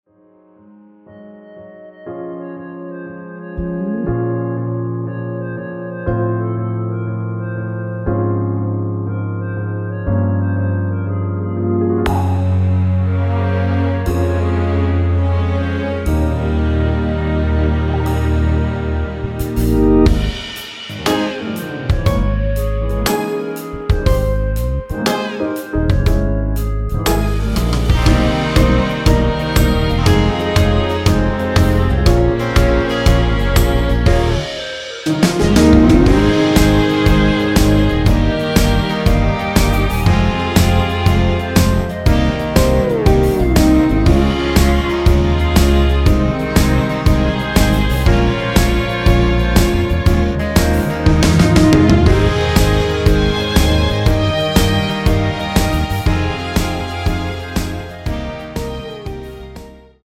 원키에서(-2)내린 멜로디 포함된 MR입니다.(미리듣기 확인)
Ab
앞부분30초, 뒷부분30초씩 편집해서 올려 드리고 있습니다.
중간에 음이 끈어지고 다시 나오는 이유는